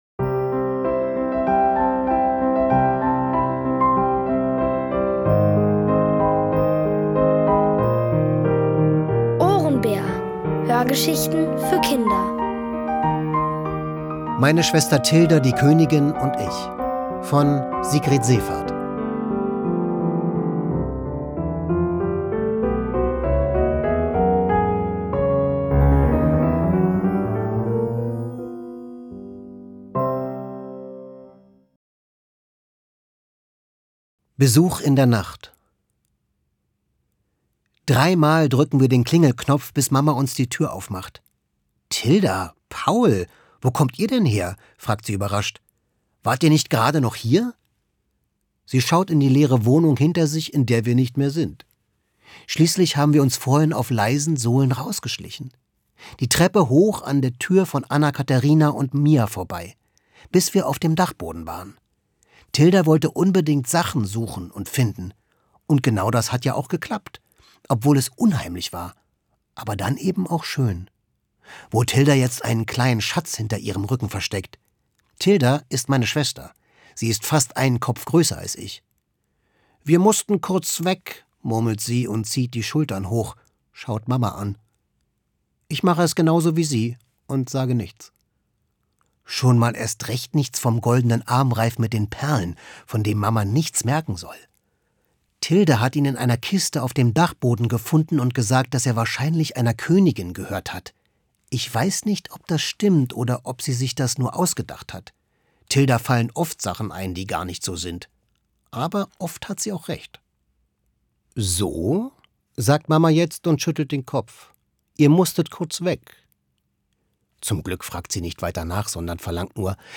Von Autoren extra für die Reihe geschrieben und von bekannten Schauspielern gelesen.
Es liest: Florian Lukas.